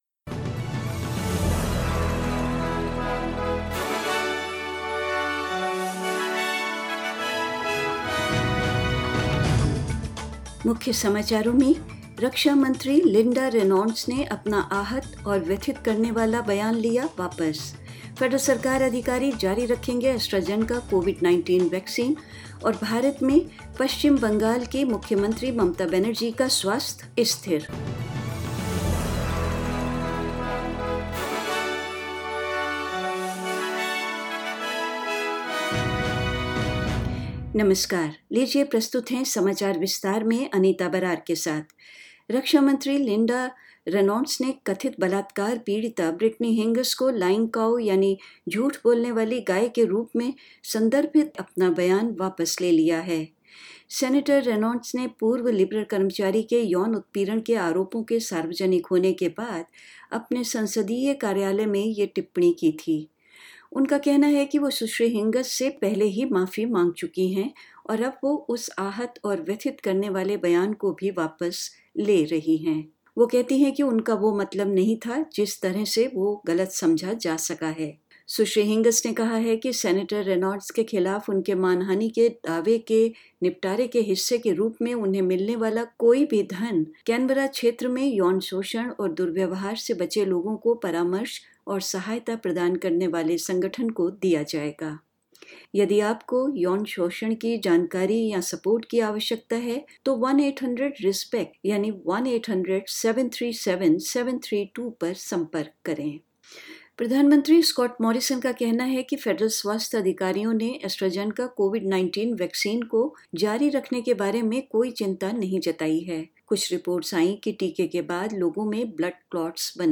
News in Hindi: Inaugural Quad leaders meeting reflects increasing interest in Indo-Pacific matters